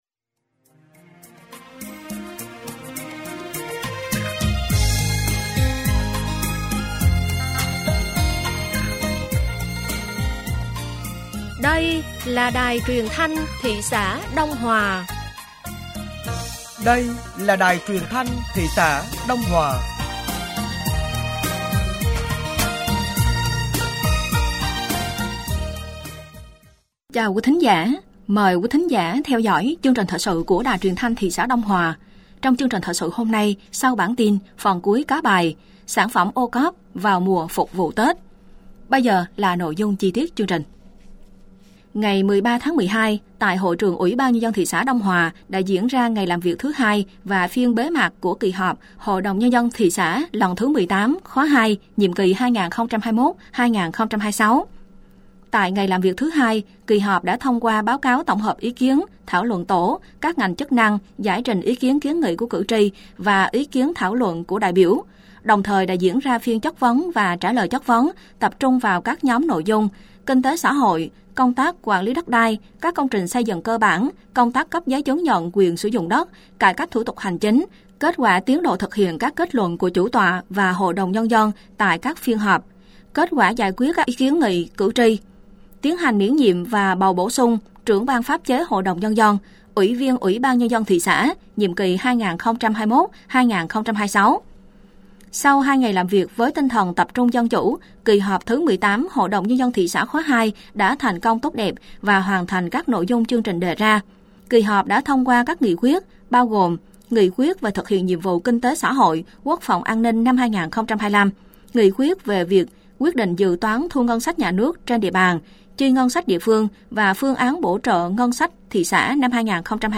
Thời sự tối ngày 13 và sáng ngày 14 tháng 12 năm 2024